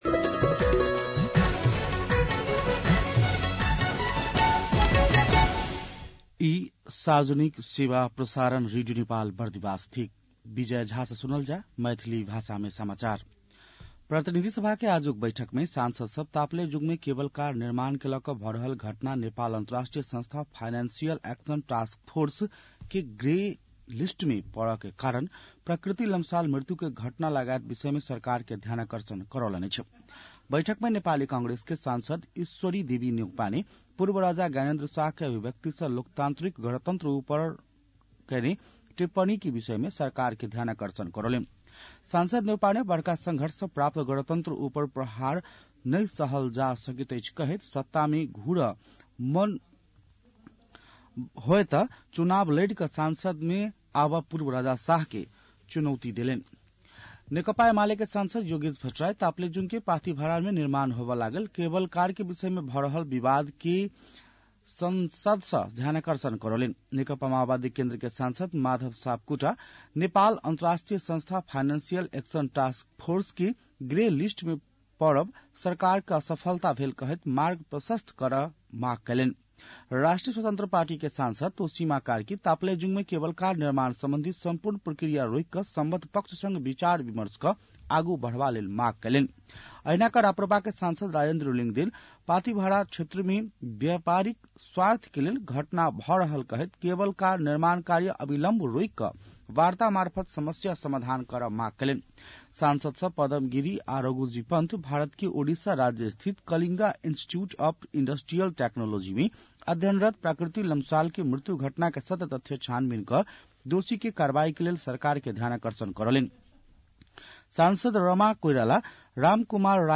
मैथिली भाषामा समाचार : १२ फागुन , २०८१